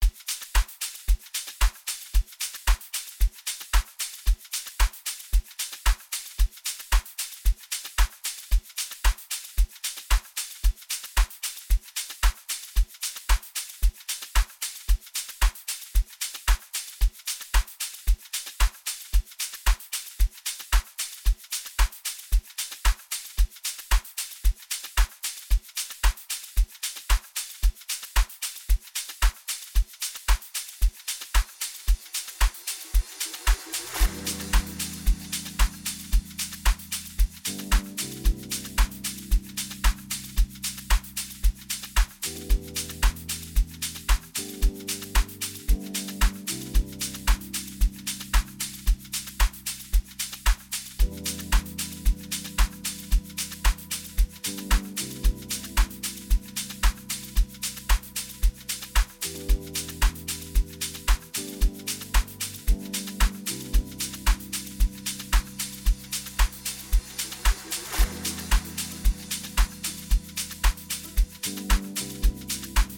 smooth and velvety vocals
a beautiful fusion of soulful house and amapiano elements
intricate melodies and infectious beats